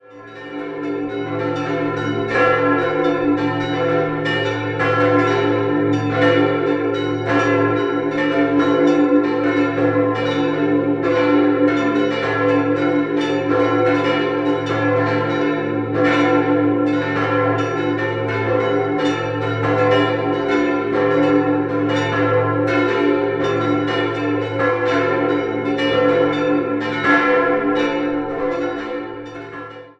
Von der ursprünglichen Ausstattund sind nur Teile erhalten. 5-stimmiges Geläut: h°-e'-a'-fis''-a'' Die kleinste Glocke wurde 1789 von Joachim Keller in Bamberg gegossen, Nr. 4 ist unbezeichnet und stammt wohl aus der Zeit um 1400, die drei übrigen entstanden 1651/52 bei Andreas Limmer in Kronach.